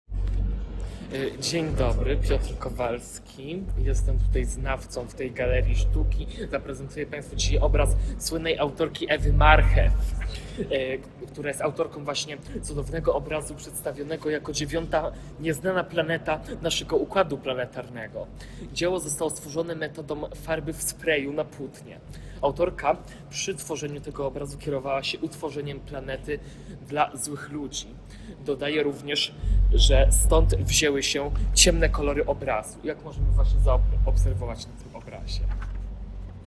Moving Image. Intervention: Audioguide in Polish